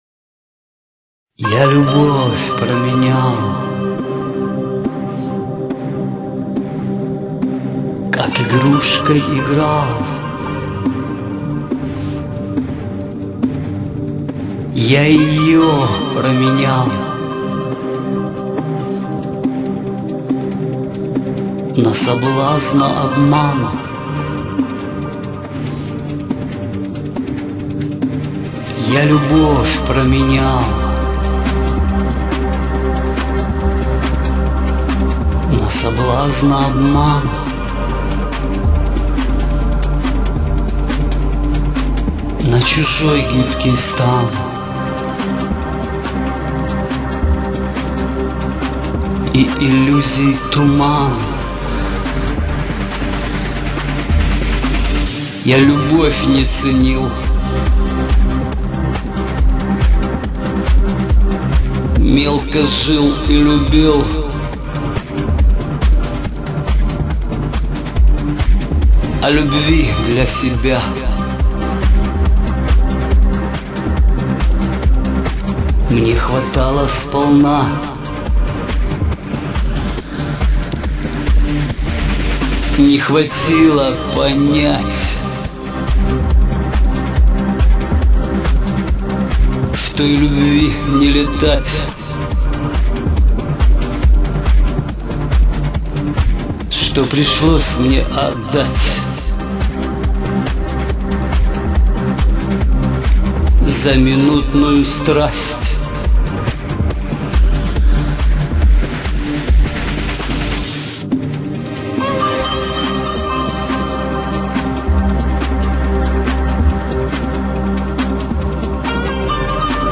И получилась, по моему, очень мелодичная и красивая песня.